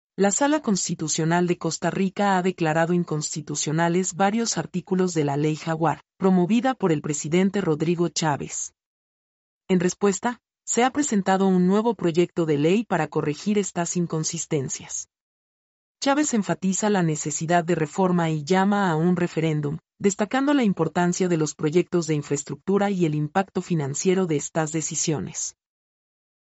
mp3-output-ttsfreedotcom-44-1.mp3